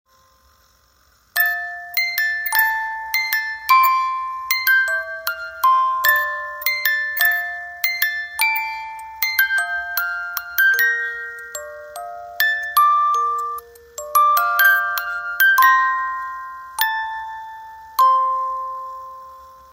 Cover , Romántico